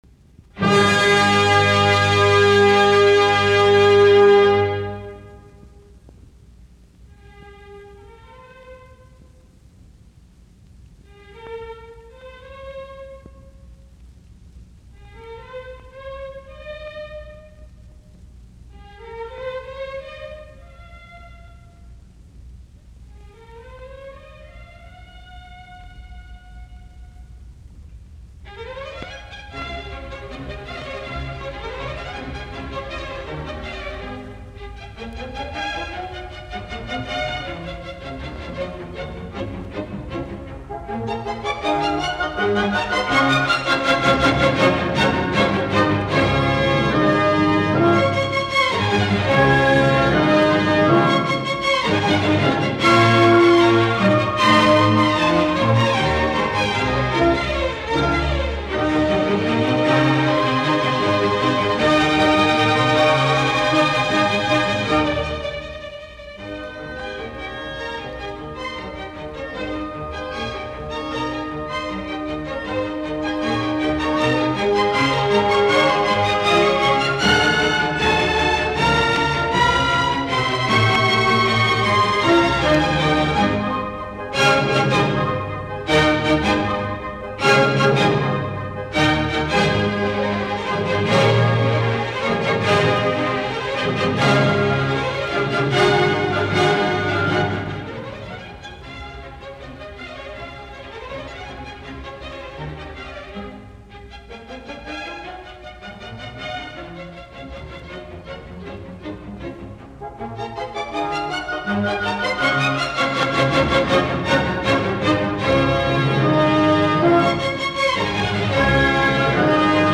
r1952, Carnegie Hall, New York.